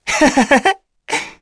Neraxis-Vox_Happy1_kr.wav